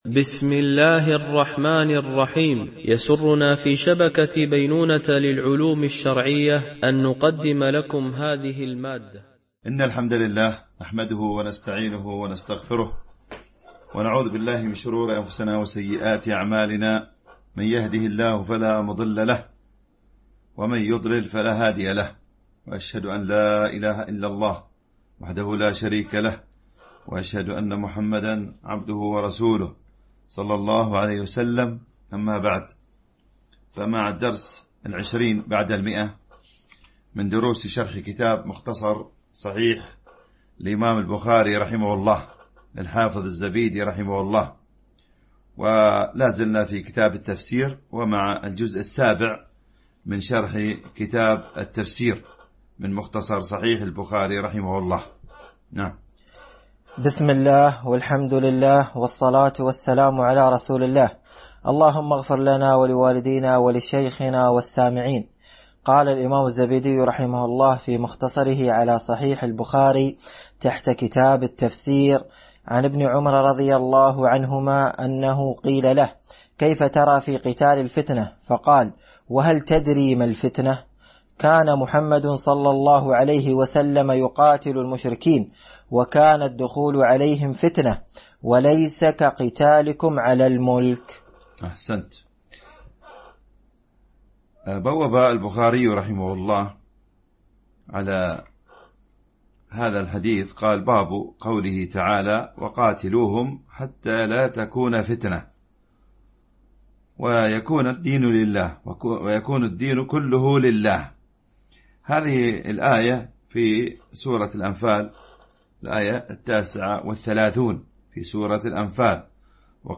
شرح مختصر صحيح البخاري ـ الدرس 120 ( كتاب التفسير ـ الجزء السابع ـ الحديث 1745 - 1750 )